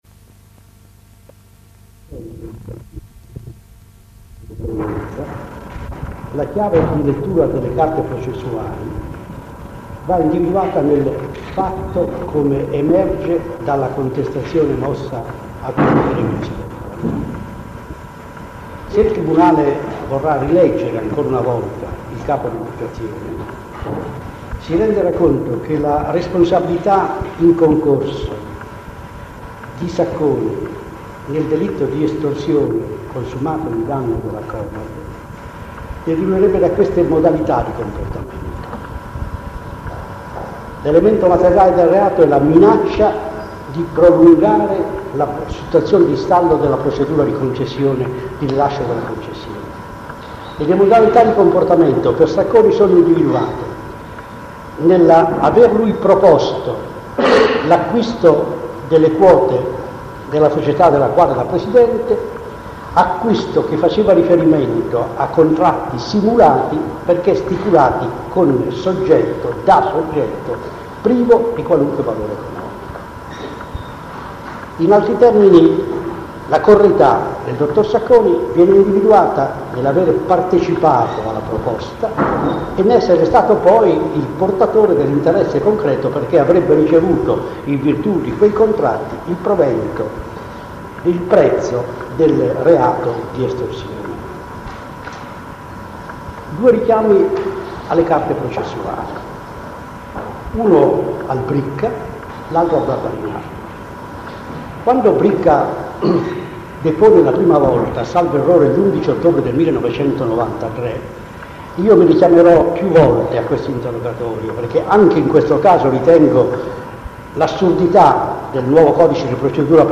L'arringa finale